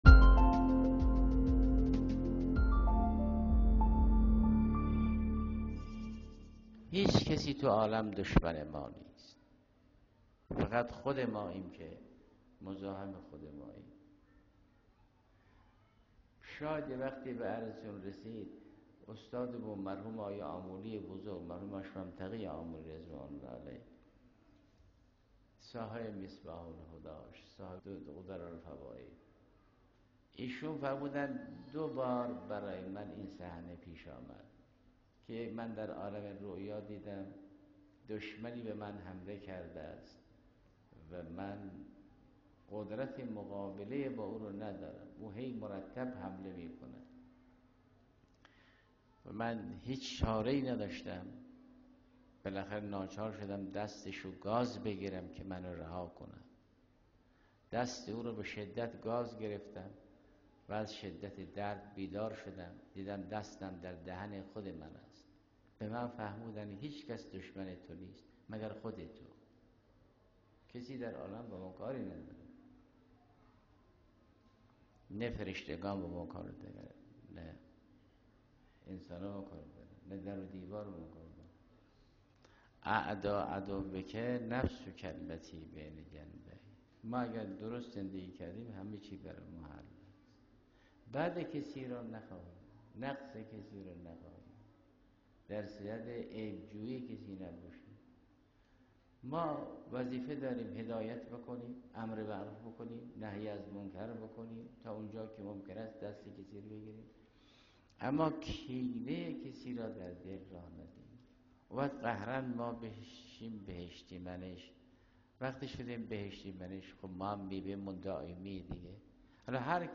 آيت‌الله جوادی آملی در درس اخلاق خود به دشمنی نفس با فرد اشاره می‌کند و آن را برترین دشمن فرد برشمرد و گفت: در دنیا هیچکس جز انسان، دشمن خود نیست.